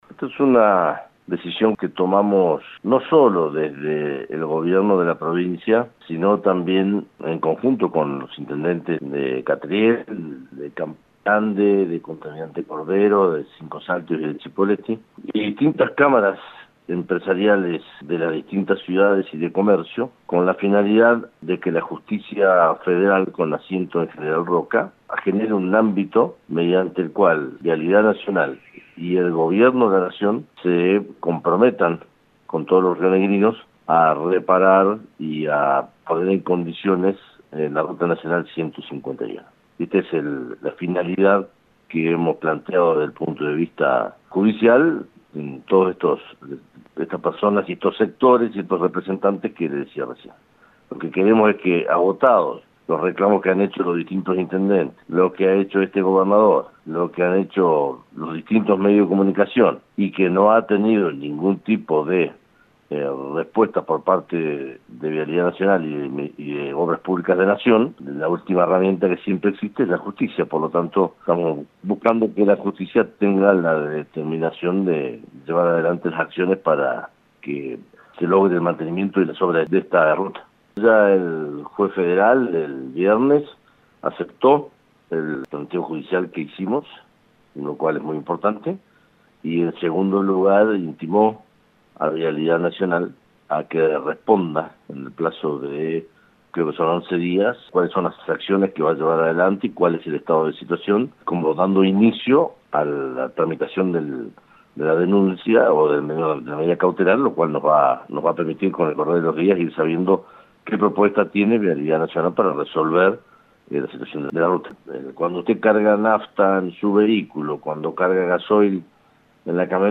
LO QUE DIJO EL GOBERNADOR DE RÍO NEGRO:
Alberto_Weretilneck-Gobernador.mp3